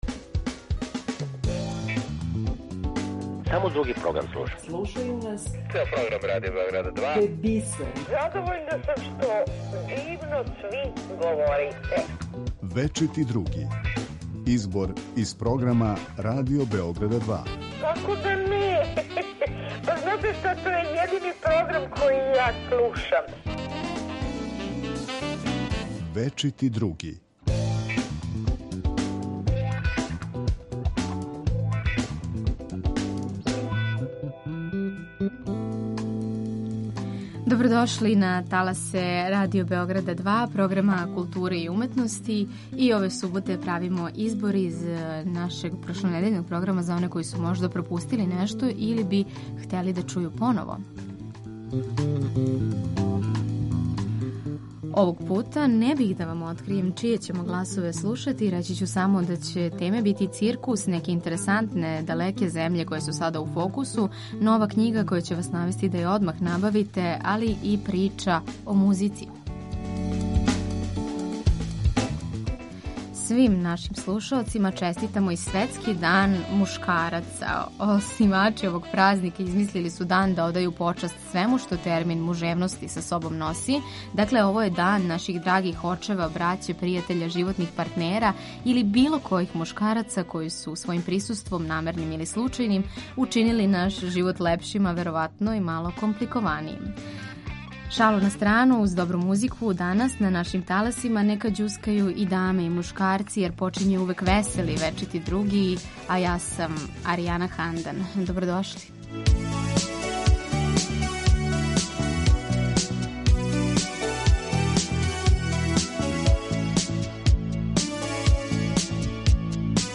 У емисији „Вечити други“ слушаоци ће моћи да чују избор најзанимљивијих садржаја емитованих на програму Радио Београда 2 током претходне и најаву онога што ће бити на програму идуће седмице.